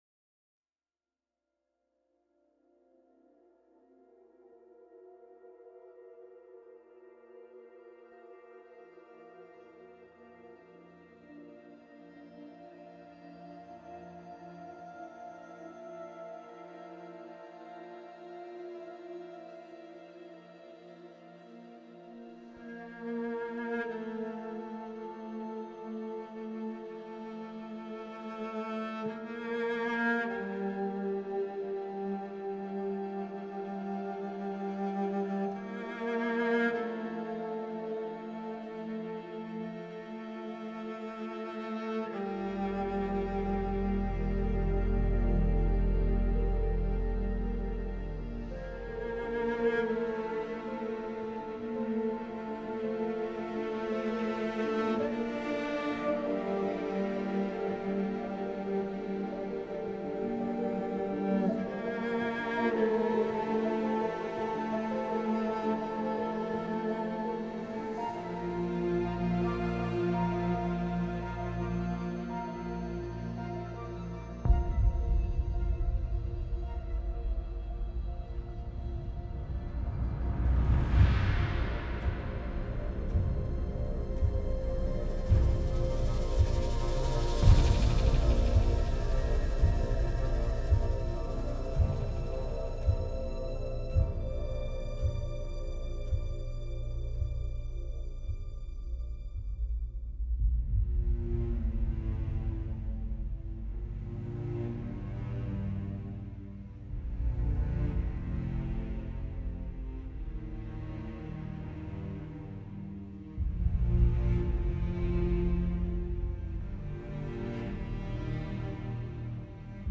موسیقی متن موسیقی بیکلام موسیقی حماسی